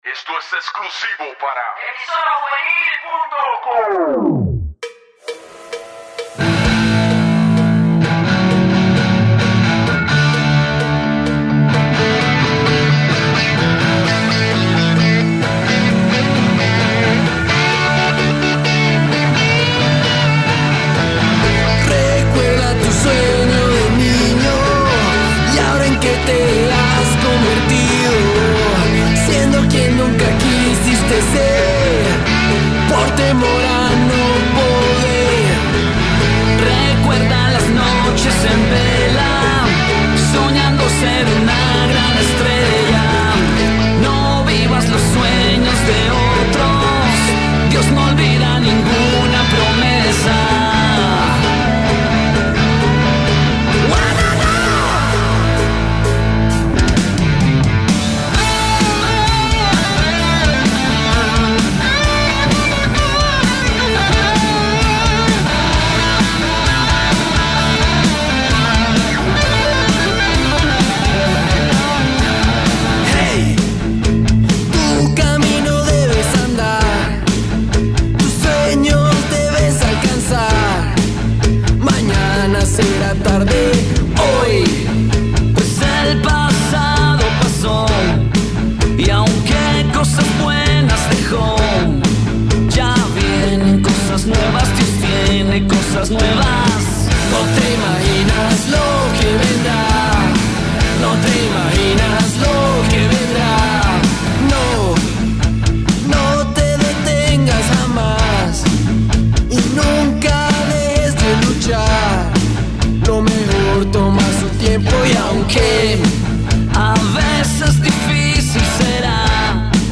Rock/Cristiano
Hard Rock Cristiano
amante de la música Rock al puro estilo de los 80